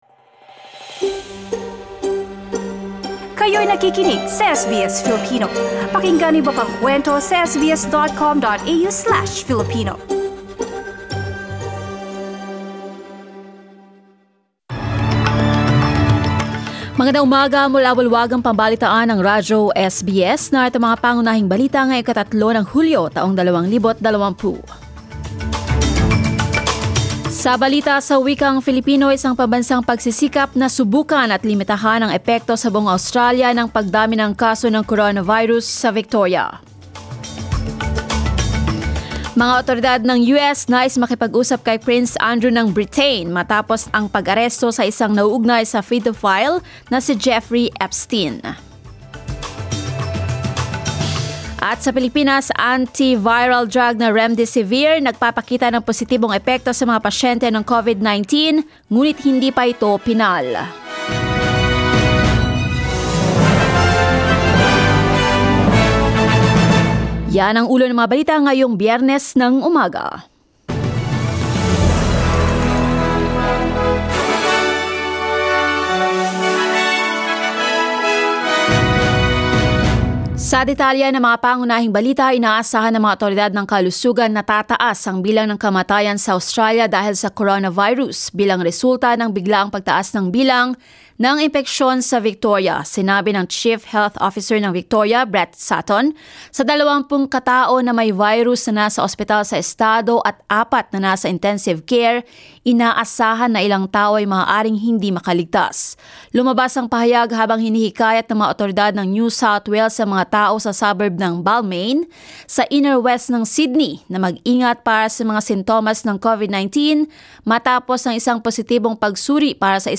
SBS News in Filipino, Friday 3 July